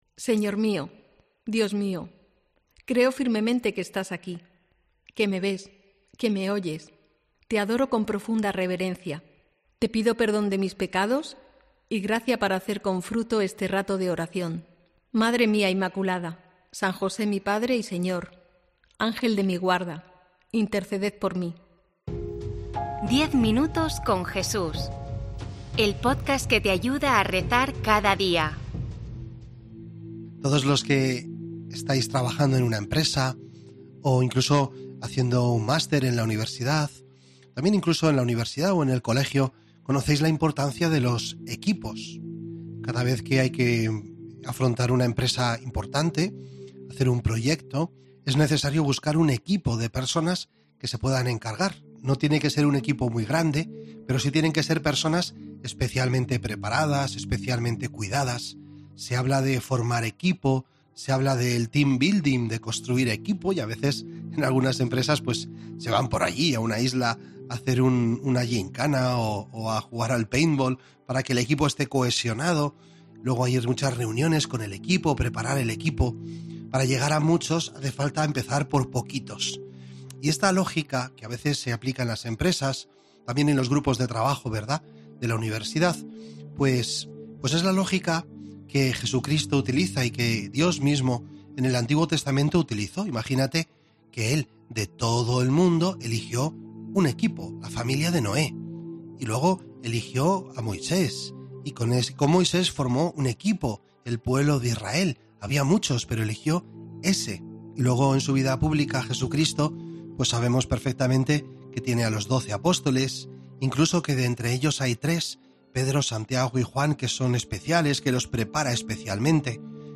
Redacción digital Madrid - Publicado el 24 abr 2024, 16:02 1 min lectura Descargar Facebook Twitter Whatsapp Telegram Enviar por email Copiar enlace COPE incorpora a su oferta de podcats '10 minutos con Jesús', una meditación diaria en formato podcast centrada en el Evangelio , en la que se proponen reflexiones y se ofrecen pinceladas sobre la vida de Jesucristo.